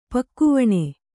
♪ pakkuvaṇe